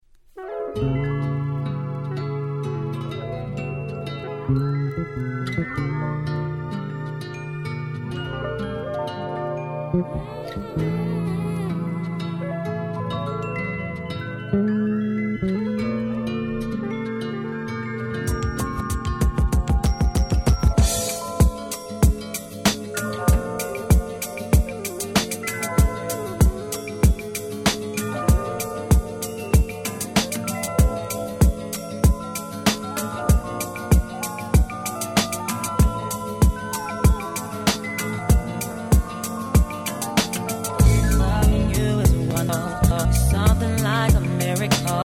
※試聴ファイルは別の盤から録音してございます。
99' R&B Classic !!
Soulfulなオケに彼女の伸び伸びとした歌声が映えるめちゃくちゃ気持ちの良い1曲です。